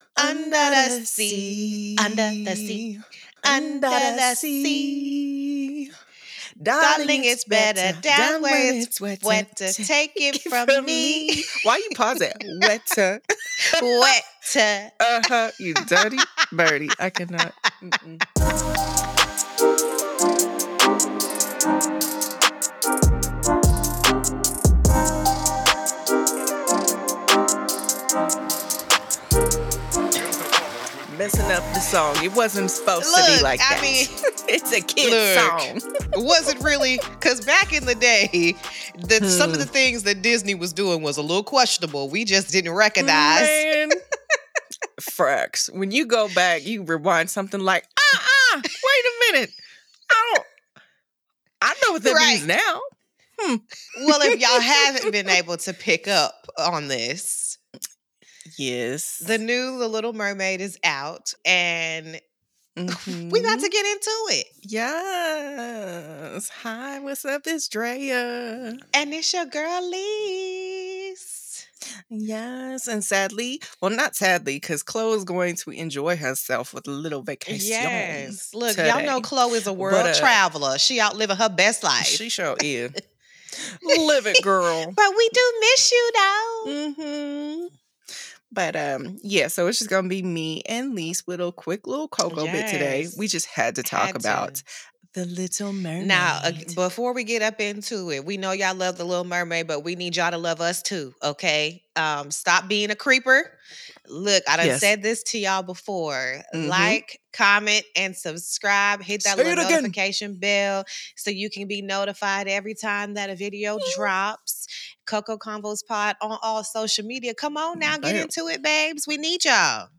Play Rate Apps Listened List Bookmark Share Get this podcast via API From The Podcast CoCo Conversations What do you get when you let 3 friends of color discuss relationships, mental health, pop culture and everything in between?